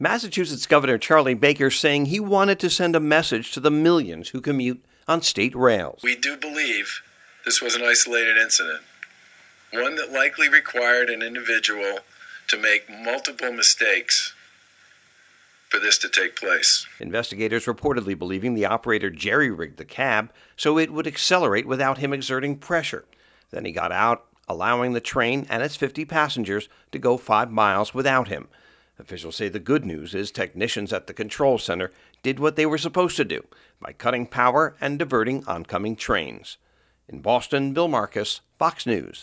(BOSTON) DEC 11 – OFFICIALS IN MASSACHUSETTS HOLDING A NEWS CONFERENCE FRIDAY TO ASSURE COMMUTERS AFTER AN INCIDENT THURSDAY MORNING IN WHICH A RED LINE TRAIN TRAVELED FOR NINE MINUTES WITH 50 PASSENGERS AND NO OPERATOR.